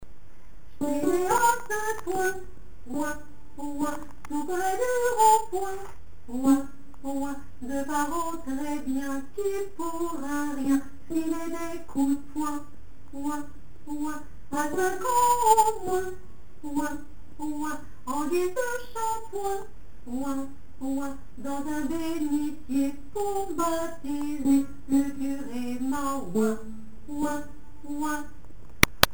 Soprano
ouinouin2_Alto.mp3